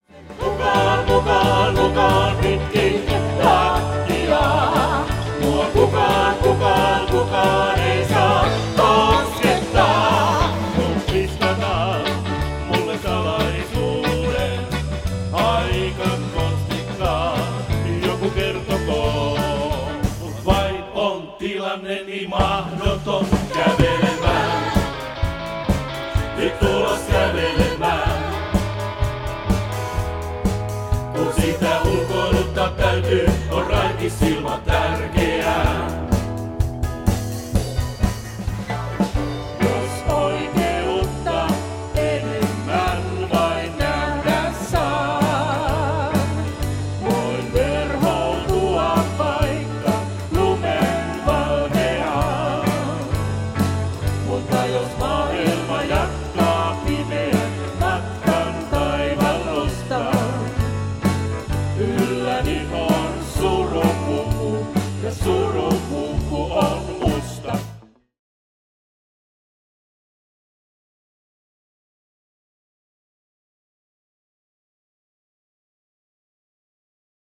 Menneiden vuosikymmenten hittejä hyvällä meiningillä
Lauluyhtye
cover-kappaleita, omaa tuotantoa